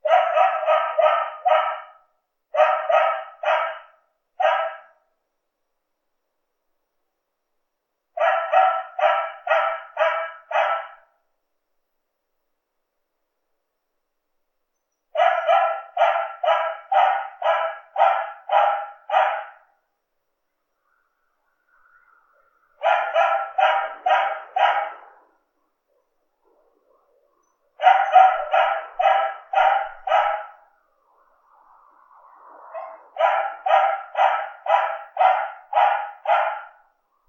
perro ladrando1
dog_barking1.mp3